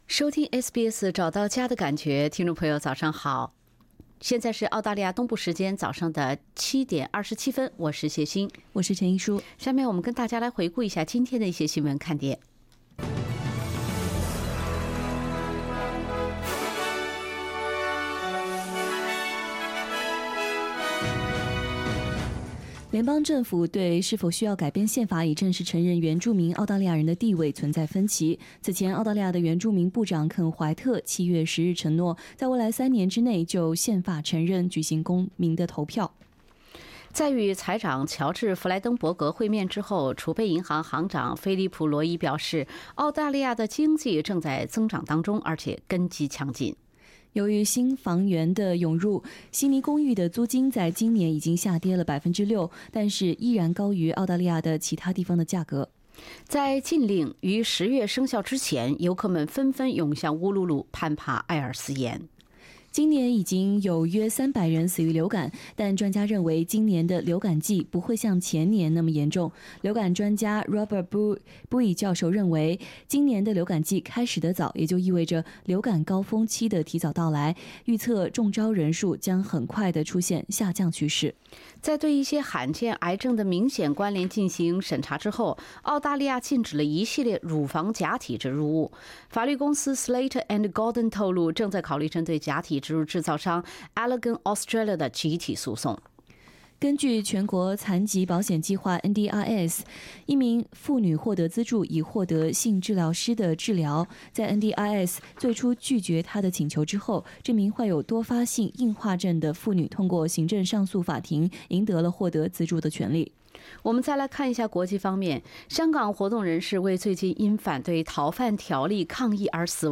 SBS 早新闻 （07月12日）